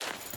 Dirt Chain Jump.ogg